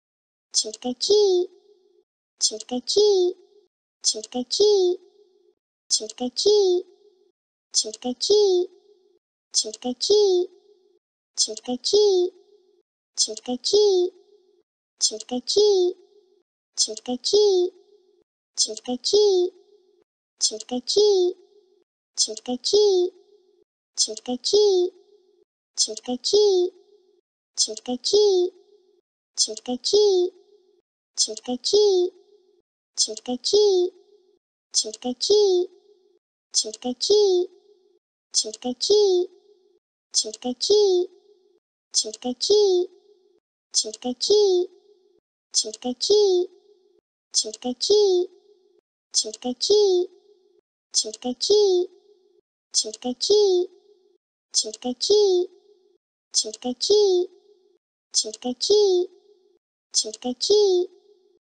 Kid Trick Or Treat Sound
meme